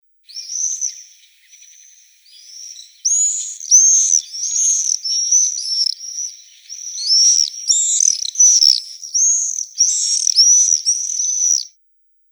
Погрузитесь в мир звуков стрижей – их звонкое щебетание напомнит о теплых летних днях.
Пение черного стрижа: звуки в полете